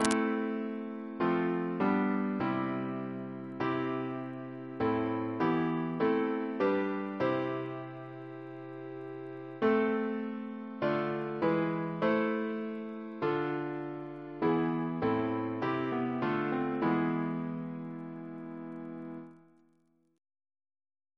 Double chant in A minor Composer: Mary M. Bridges (1863-1949) Reference psalters: ACB: 375